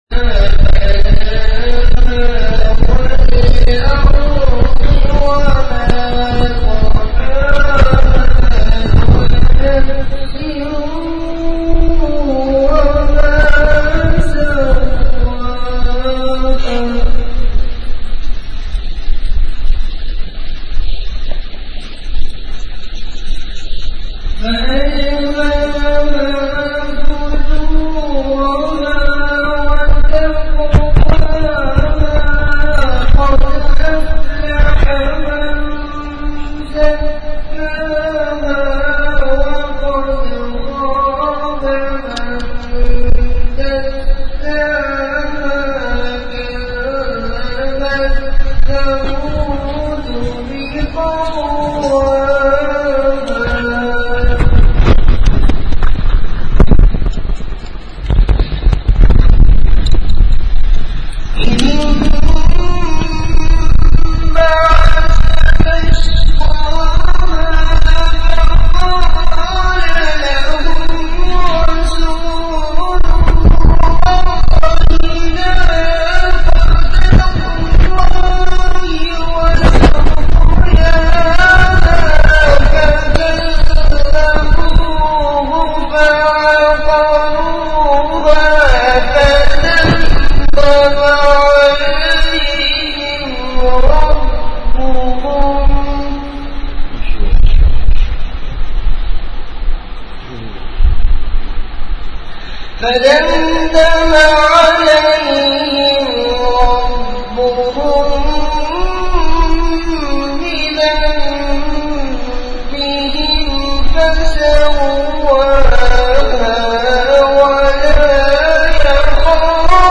Category Bayanat
Event / Time After Isha Prayer